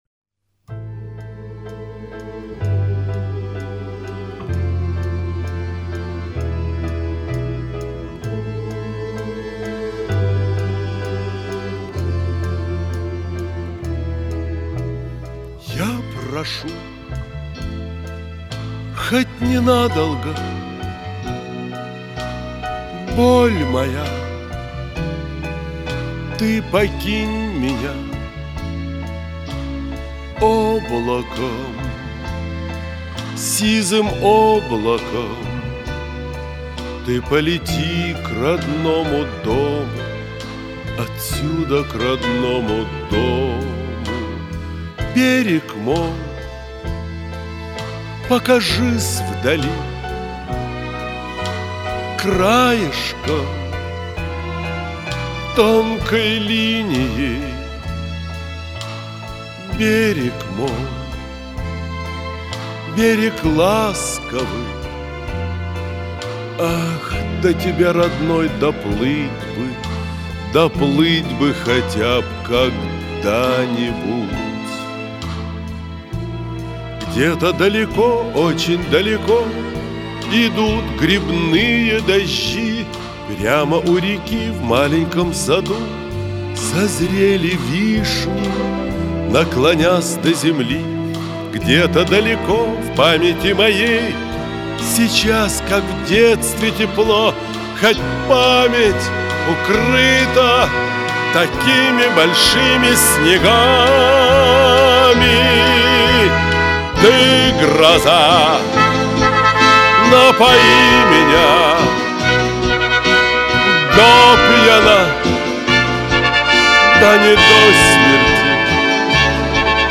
Исполнение, видимо, концертное.